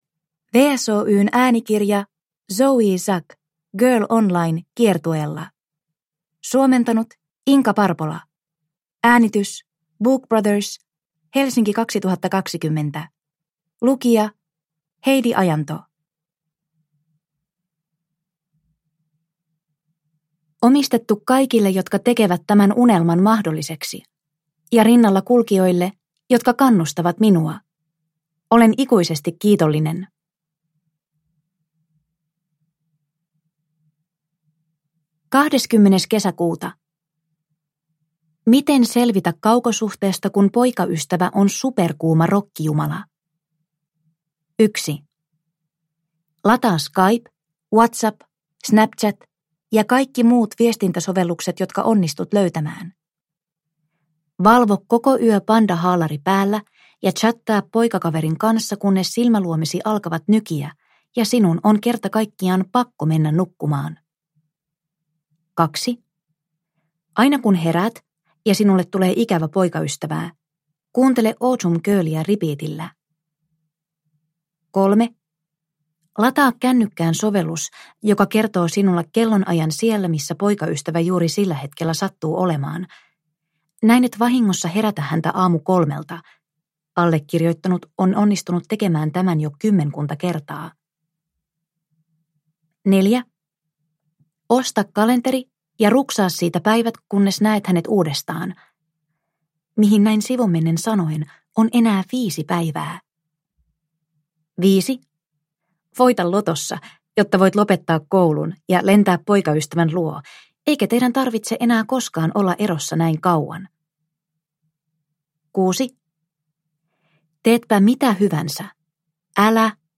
Girl Online kiertueella – Ljudbok – Laddas ner